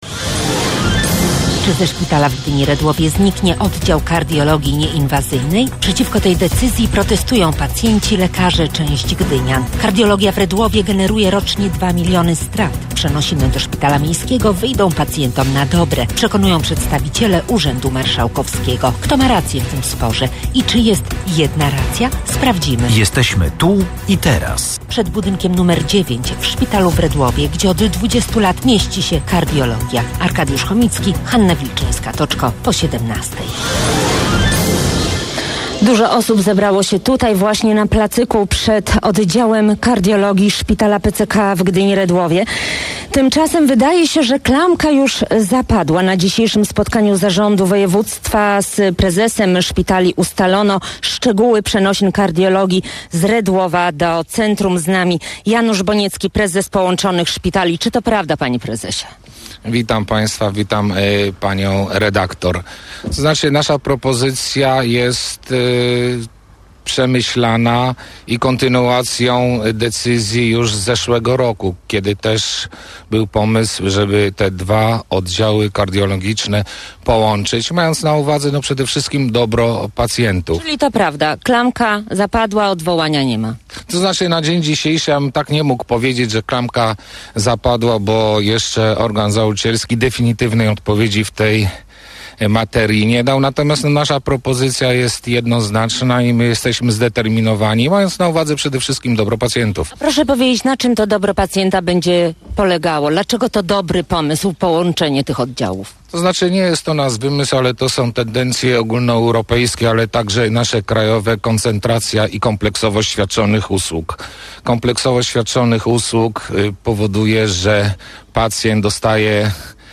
Sprawą zajęła się ekipa programu Tu i teraz, nasz wóz satelitarny zaparkował przed budynkiem nr 9 Szpitala w Redłowie, gdzie przez 20 lat funkcjonowała kardiologia.